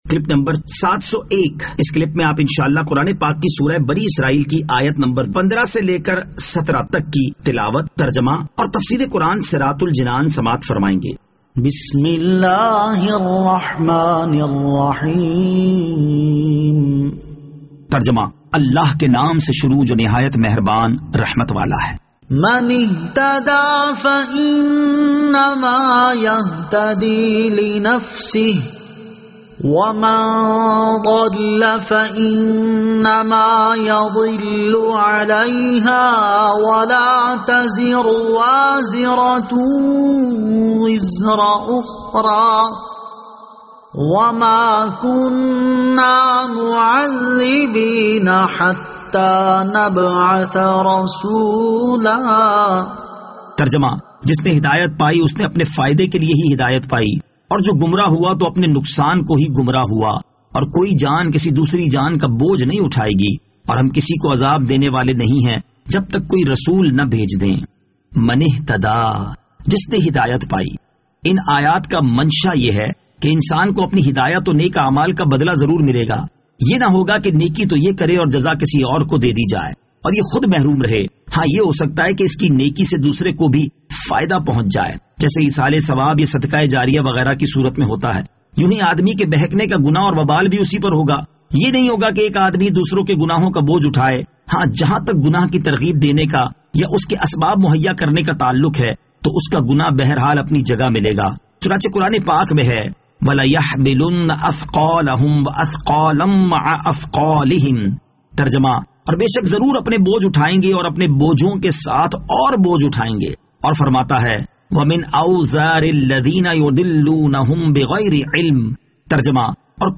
Surah Al-Isra Ayat 15 To 17 Tilawat , Tarjama , Tafseer
2021 MP3 MP4 MP4 Share سُوَّرۃُ الاسٗرَاء آیت 15 تا 17 تلاوت ، ترجمہ ، تفسیر ۔